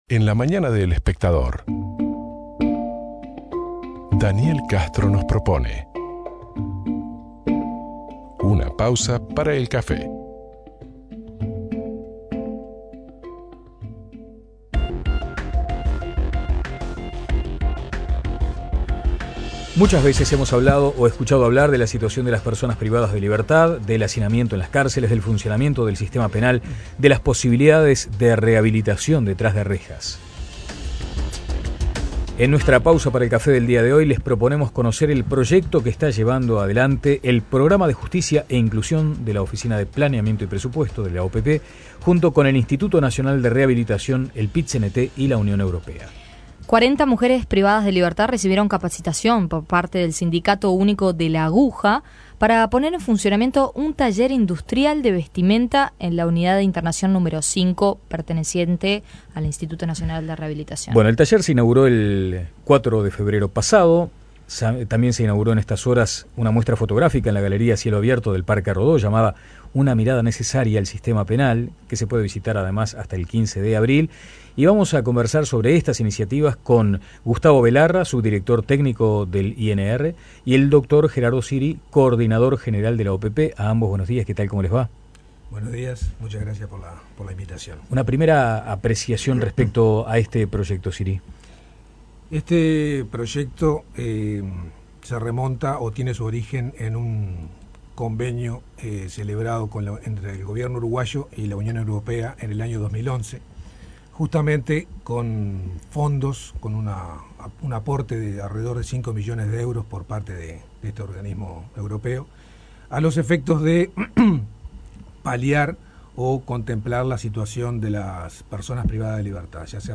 Gustavo Belarra, subdirector técnico del INR y el Doctor Gerardo Siri, Coordinador General de la OPP, hablaron sobre esta iniciativa.